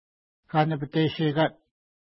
Kanapateshekat Next name Previous name Image Not Available ID: 393 Longitude: -61.9854 Latitude: 54.2786 Pronunciation: ka:nəpəteʃeka:t Translation: Mountain With a Cliff on One Side Feature: mountain